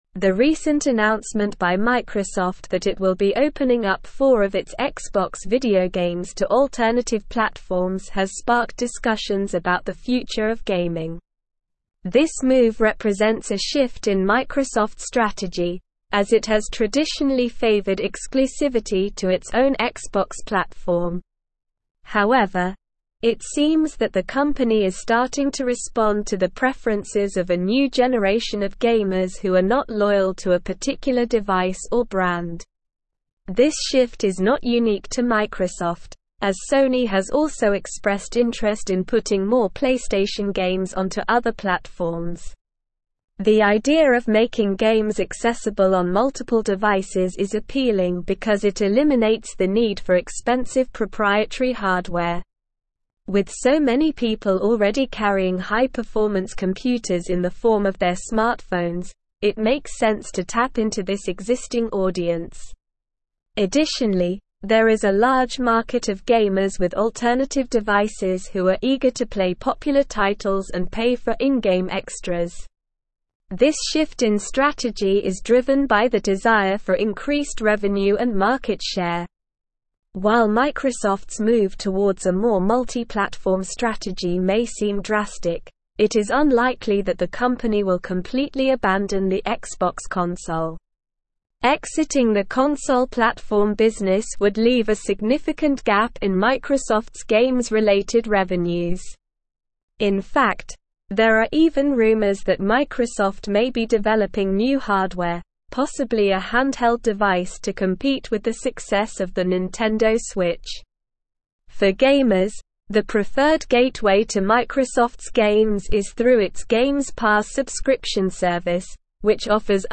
Slow
English-Newsroom-Advanced-SLOW-Reading-Microsofts-Xbox-Expands-Gaming-Strategy-to-Multiple-Platforms.mp3